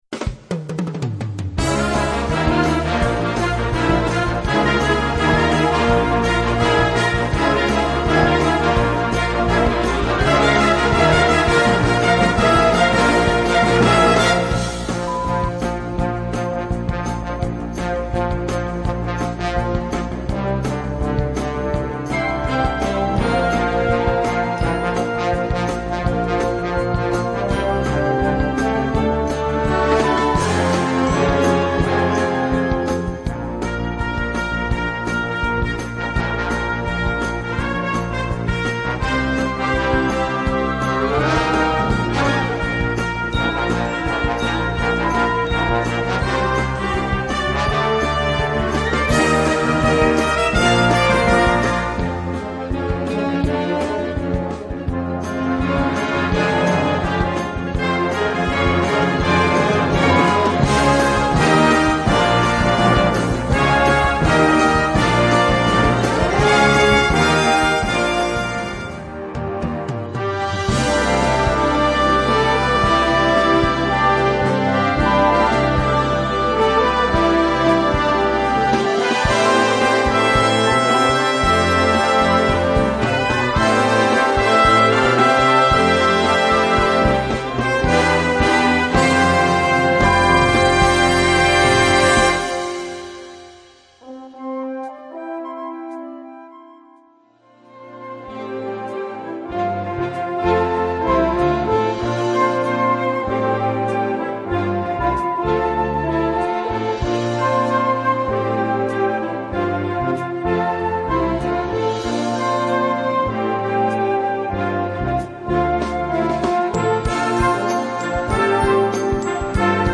Gattung: Filmmusik
Besetzung: Blasorchester
Medley für Blasorchester